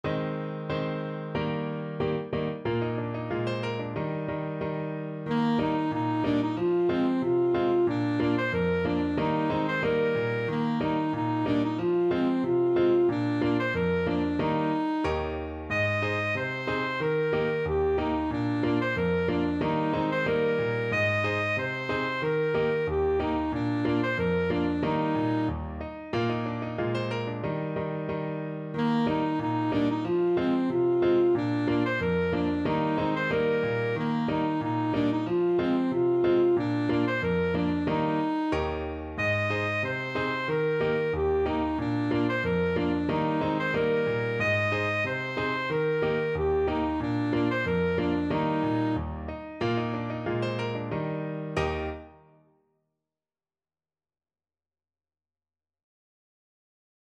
Alto Saxophone version
Alto Saxophone
2/2 (View more 2/2 Music)
Jolly =c.92
Classical (View more Classical Saxophone Music)
Swiss